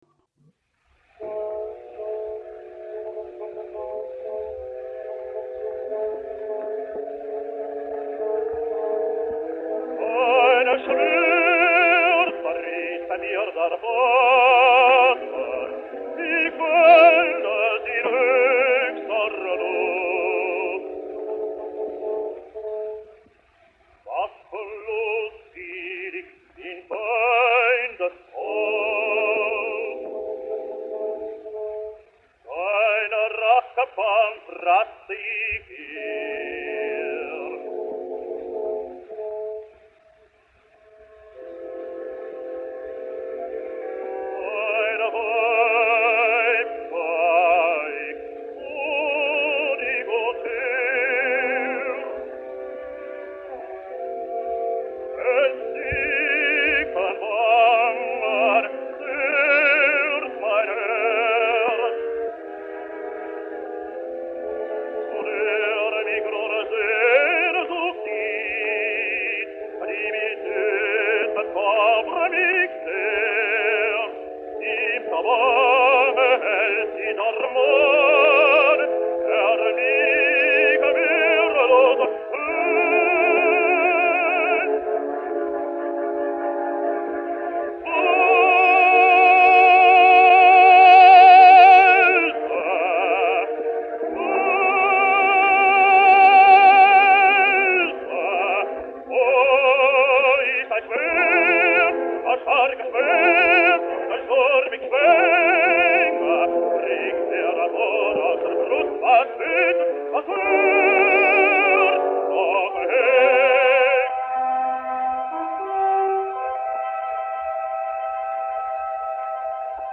Dutch Tenor.